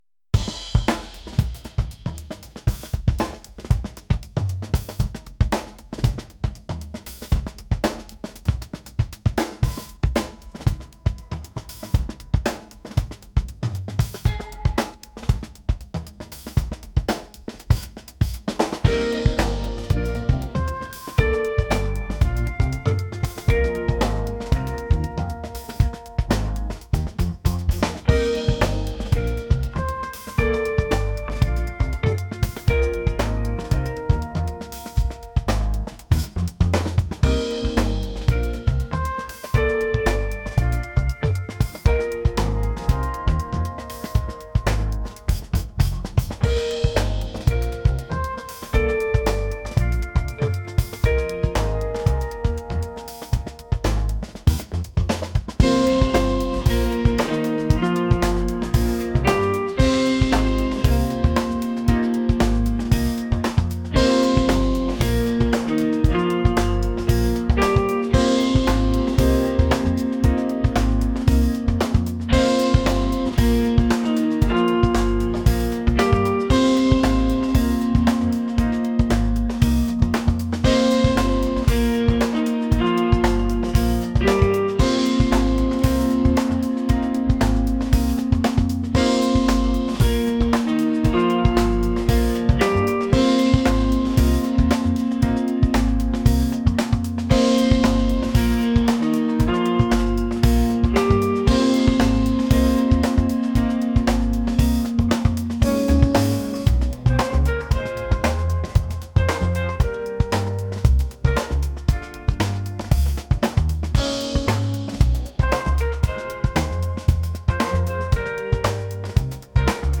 fusion | funky | jazz